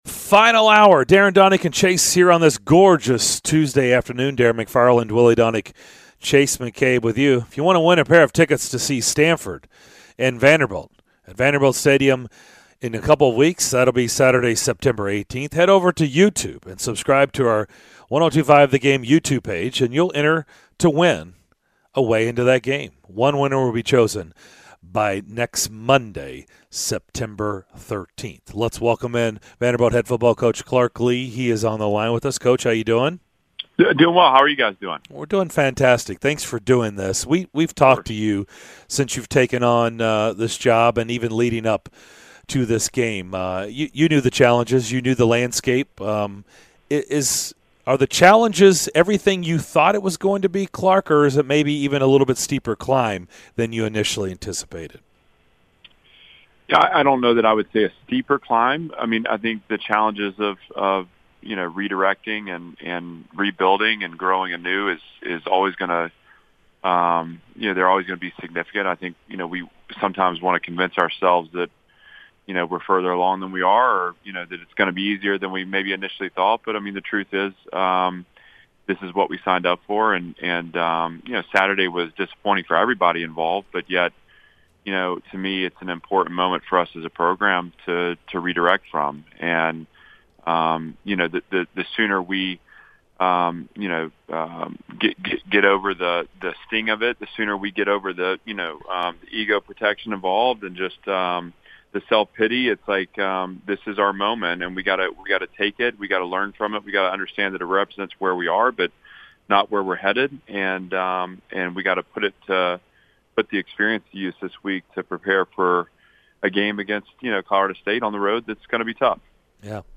Vanderbilt Head Football Coach Clark Lea joined the DDC to discuss his team's response to the disappointing season opener, looking ahead to the rest of the season, the QB situation and more!